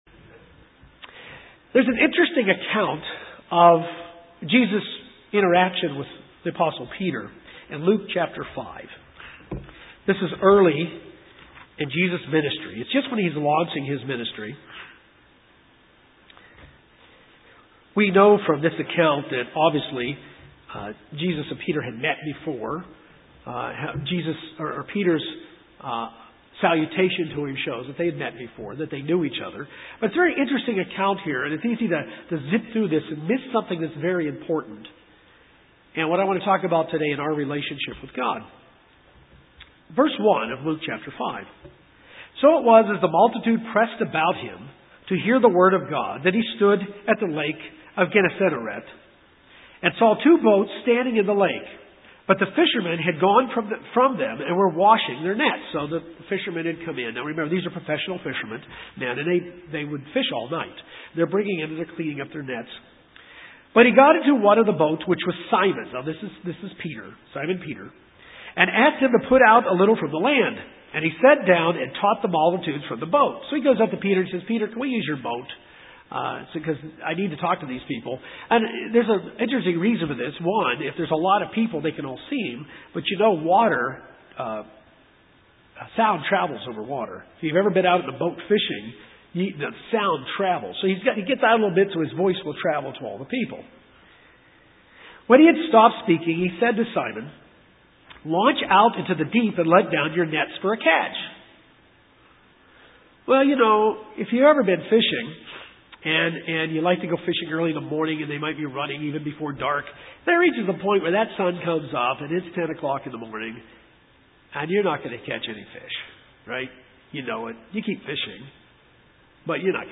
This sermon helps us understand what is needed in addition to our submission to God. It is hard at times to tell the difference in submission and total surrender to our God.